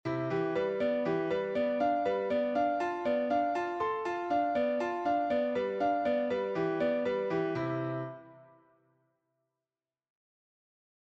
Arpèges 2 mains décalées d’une tierce
ex_arpeges.mp3